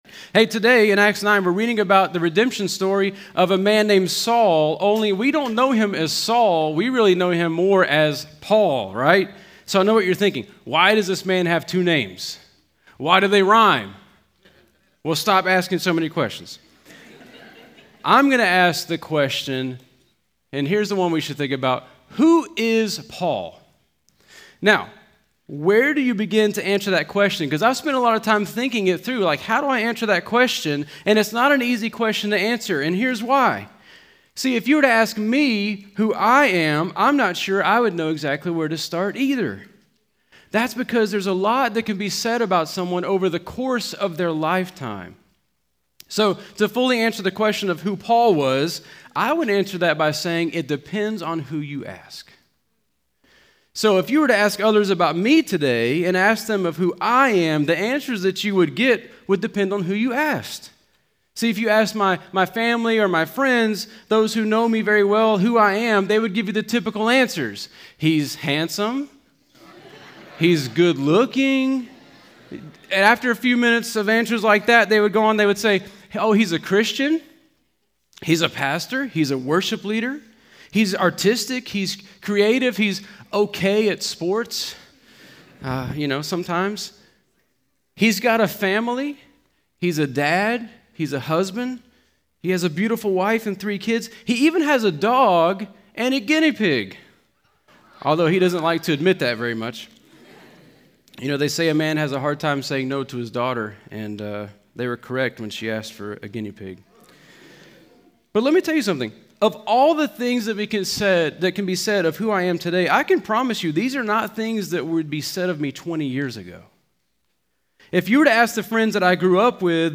Home » Sermons » Redemption Stories: Saul to Paul
Conference: Youth Conference